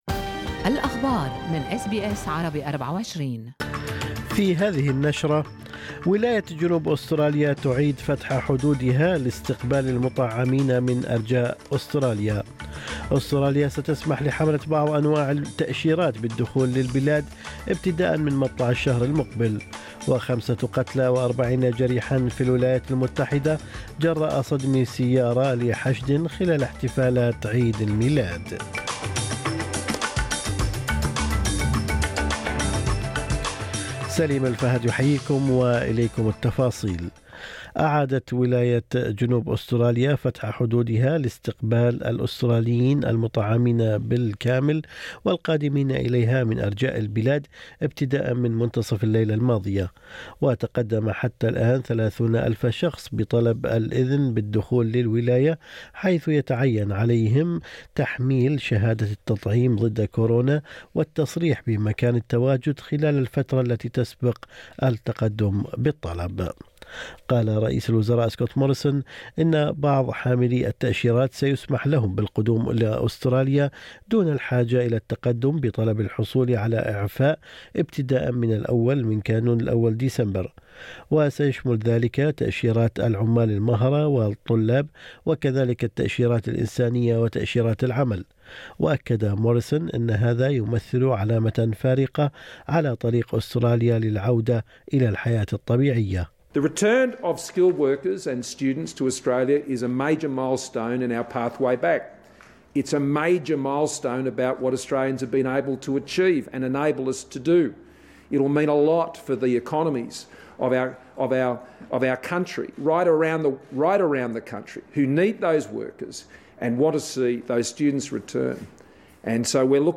نشرة أخبار الصباح 23/11/2021